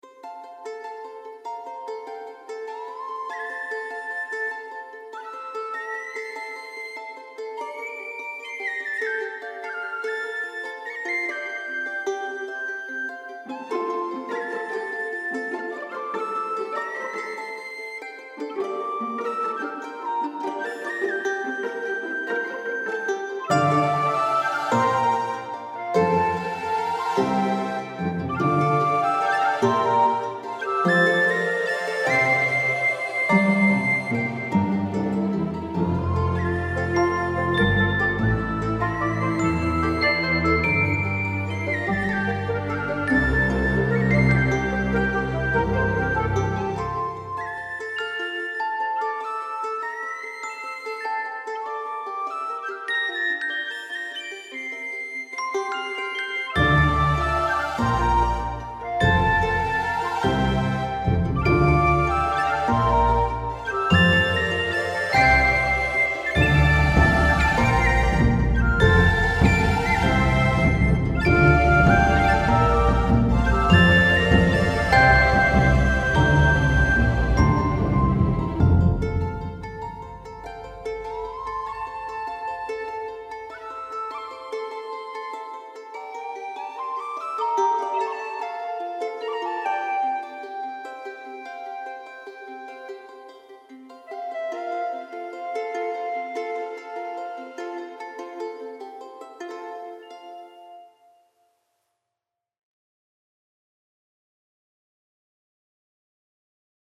MIDI
Solo